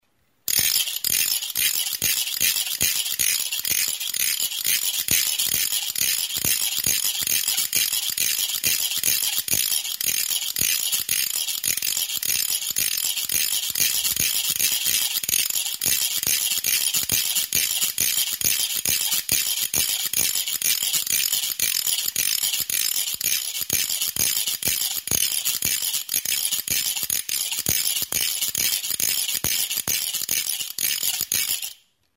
Music instrumentsCARRACA
Idiophones -> Struck -> Ratchet system
Recorded with this music instrument.
Zurezko, eskuz egindako mihi bateko karraka.